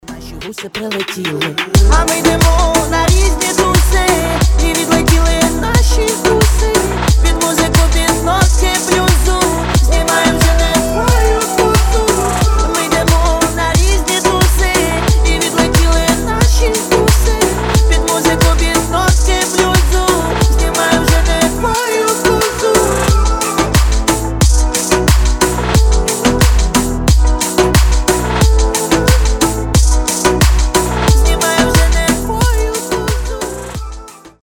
ритмичные
dancehall
ремиксы